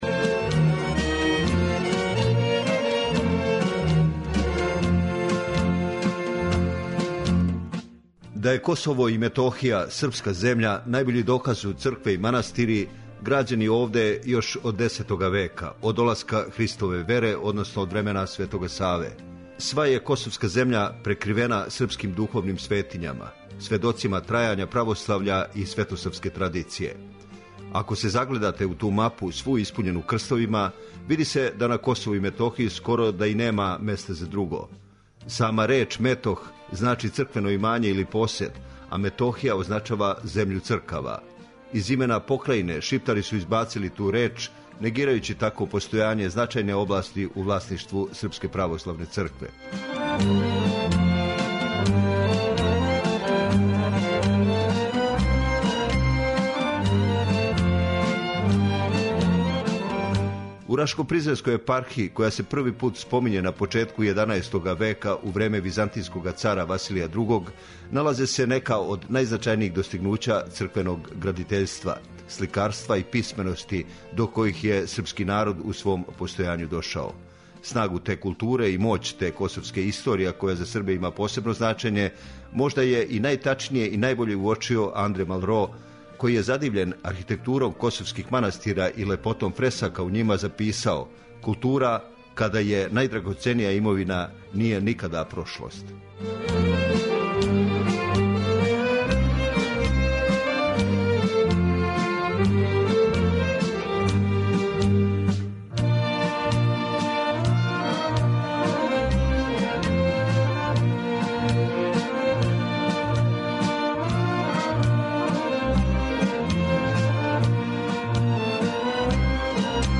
Снимак са недавно одржаног концерта под називом 'Кроз Косово и Метохију игром и песмом', који је одржан у оквиру 47. Београдских музичких свечаности.
Поред КУД "Копаоник" из Лепосавића, наступили су и певачи и свирачи из разних крајева наше јужне покрајине. Чућемо снимак старих српских песама, извођења на традиционалним инструментима, као и игре и песме сеоске и градске средине.